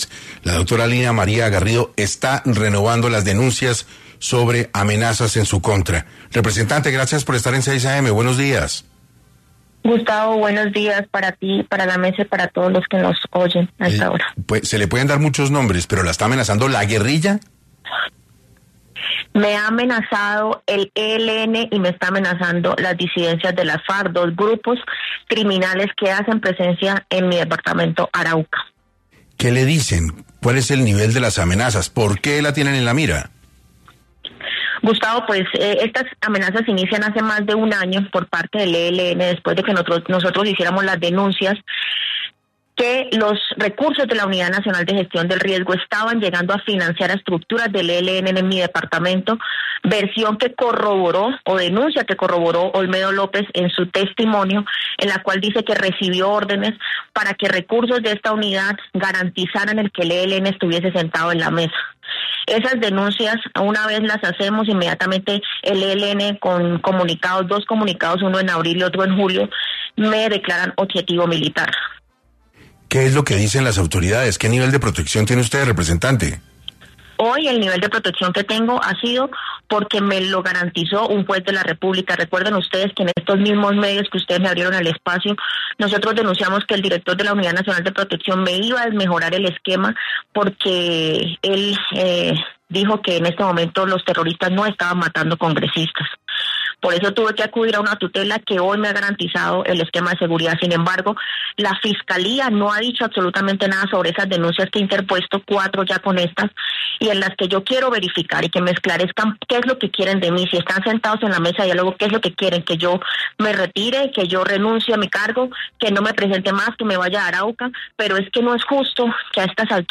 La representante Lina María Garrido advirtió en 6AM que ha recibido ya múltiples amenazas por parte de grupos al margen de la ley